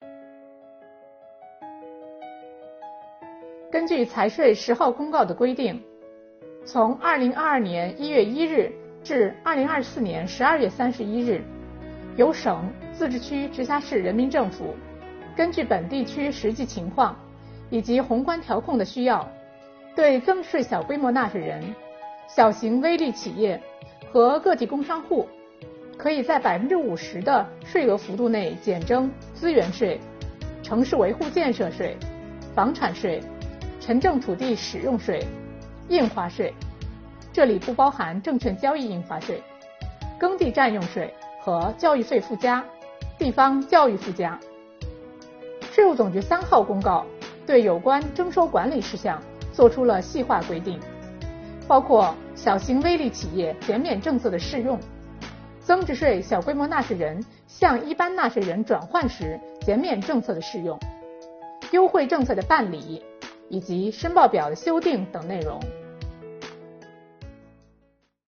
首期课程由财产和行为税司副司长刘宜担任主讲人，对“六税两费”减免政策的基本内容、系统填报、注意事项等方面进行详细讲解。